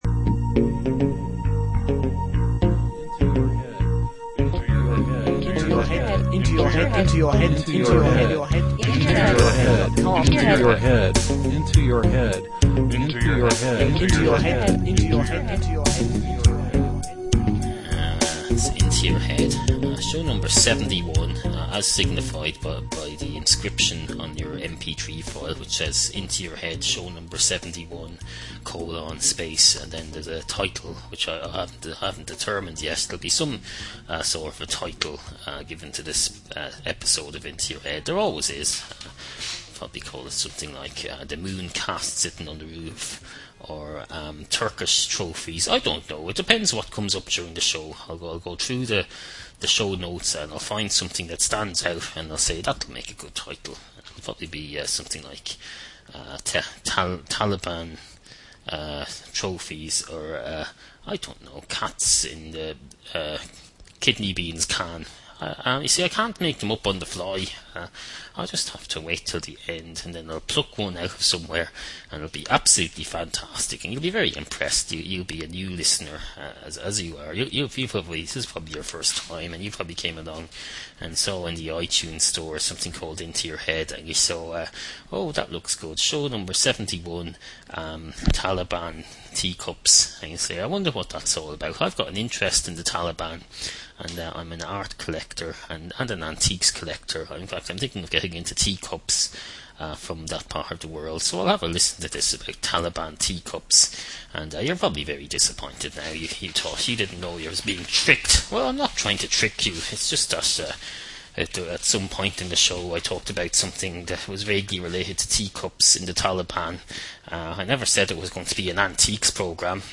Tonight’s topics include: Taliban teacup disappointments, Satanic packaging, Child tea manufacturers, Differing attitudes to pet insects, A loophole in the tea selling laws, Greener tea, A politics-related hand mixup, Gift tax on beverages, Scientists with too much power, The monkey island experiment, Creating safer tree climbers, Weak tea bags and compost, Improvising with a sweeping brush, White wooly island garments, When to order hot burgers, Bunny-shaped sausage, Cottaging in the fast food sector, Replacing potatoes with bread, Arán versus Árann, Dazzled by the television, Avoiding Wikipedia, Issues with a green search engine, Accepting one twenty-billionth of the responsibility. Also: We advance the cat song genre another step using what’s available in the kitchen, and preview upcoming new character that we’re developing.